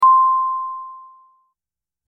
Elevator Ding 02
Elevator_ding_02.mp3